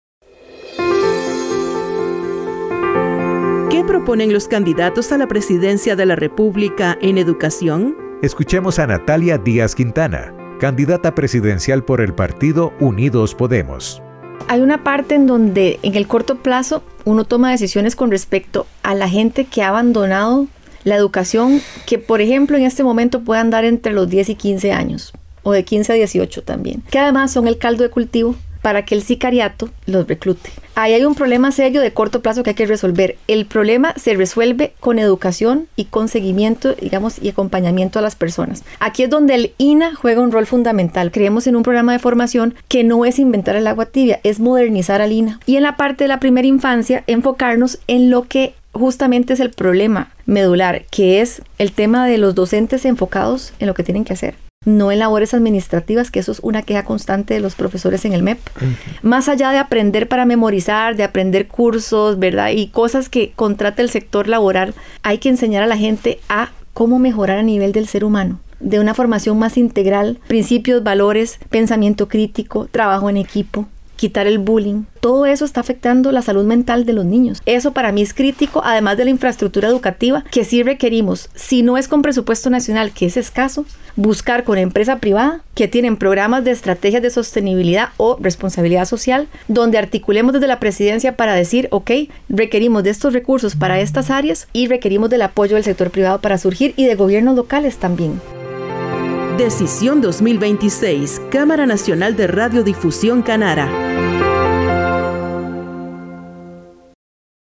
Escuchemos a Natalia Díaz Quintana, candidata del Partido Unidos Podemos.